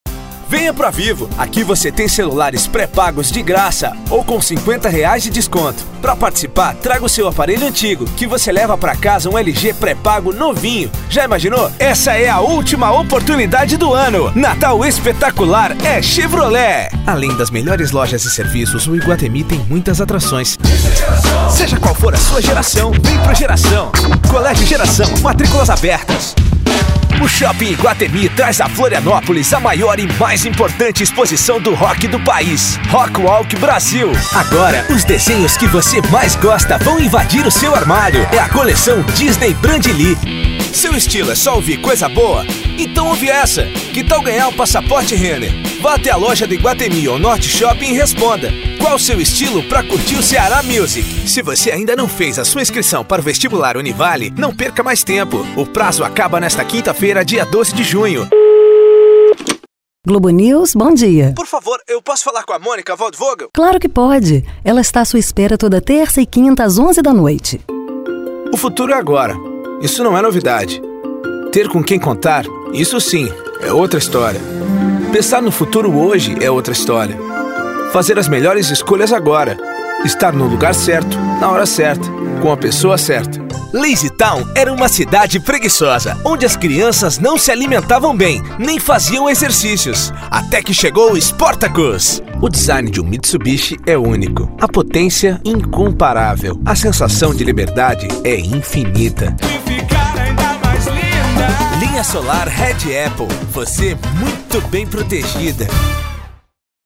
Perfil do Locutor | StartStudio
Mix de áudios